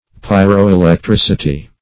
pyroelectricity - definition of pyroelectricity - synonyms, pronunciation, spelling from Free Dictionary
Pyroelectricity \Pyr`o*e`lec*tric"i*ty\, n. (Physics)